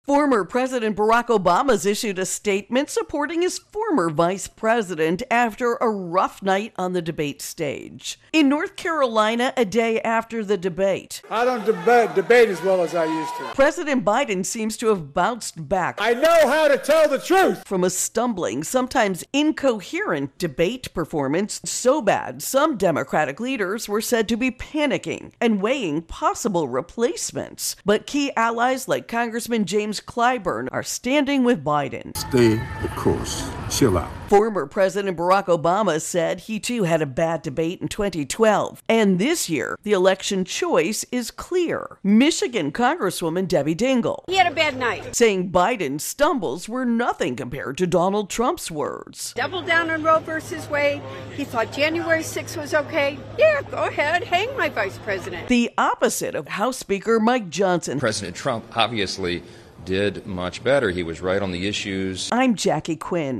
reports on key Democrats stepping forward to support President Biden, after his poor performance during the Thursday night televised debate.